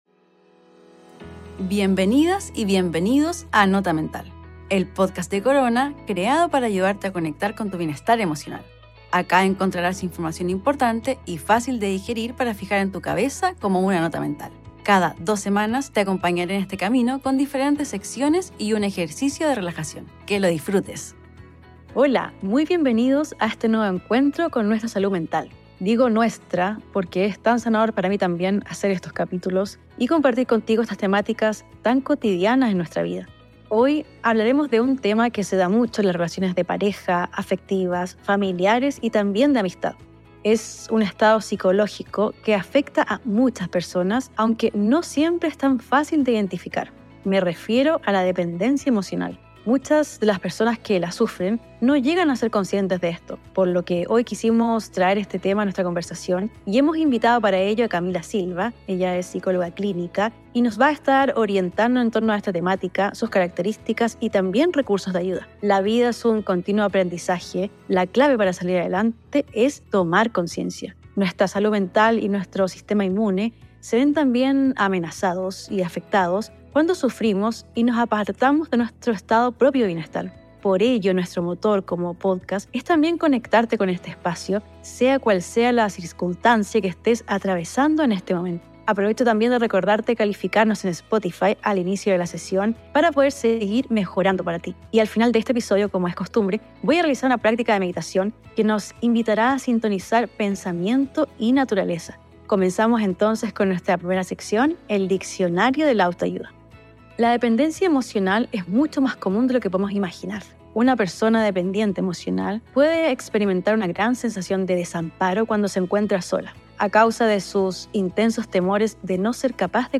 Y quédate hasta el final, porque hoy realizaremos una práctica de meditación que te ayudará a sintonizar tu pensamiento con la naturaleza.